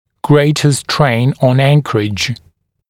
[‘greɪtə streɪn ɔn ‘æŋk(ə)rɪʤ][‘грэйтэ стрэйн он ‘энк(э)ридж]большая нагрузка на опорные участки